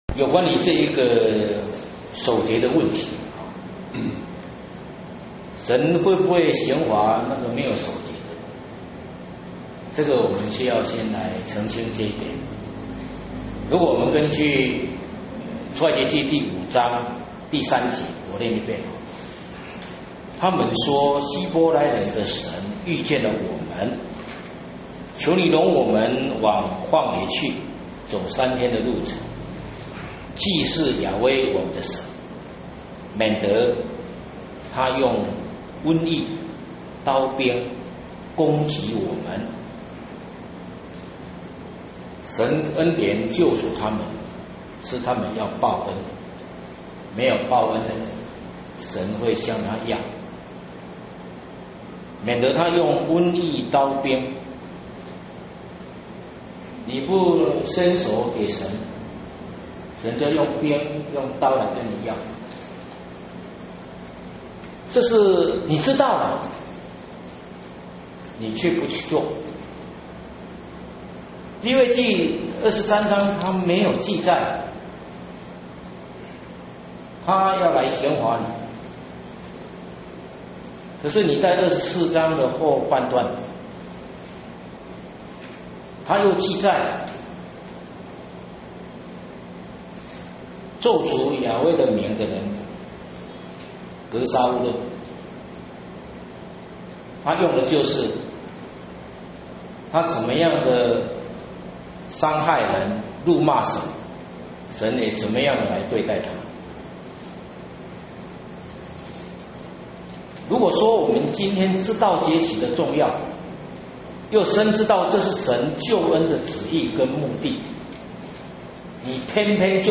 2015年五旬節聖會-詩頌